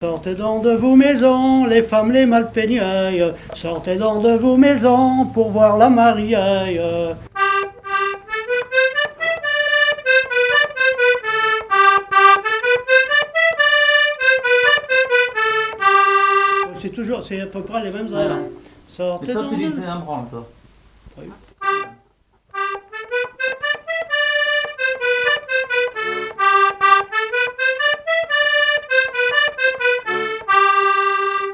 Mémoires et Patrimoines vivants - RaddO est une base de données d'archives iconographiques et sonores.
Genre énumérative
Pièce musicale inédite